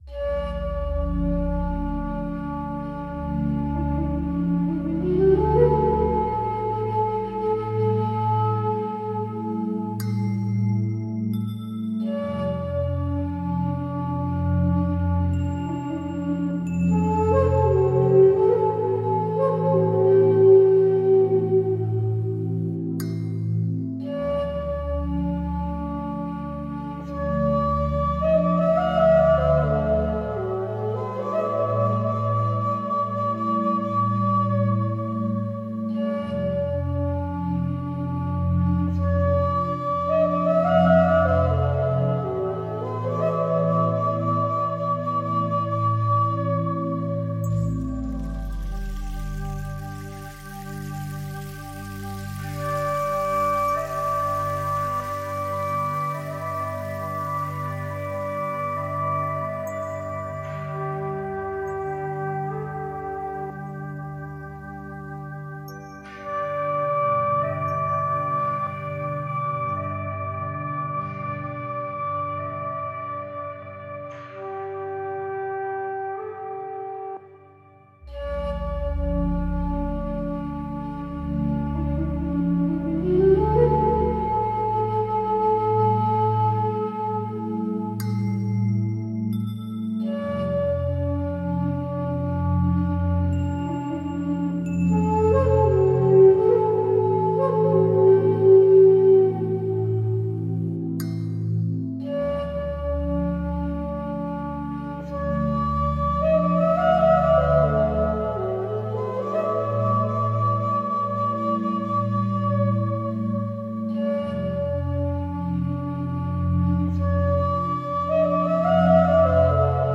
Singer : Instrumental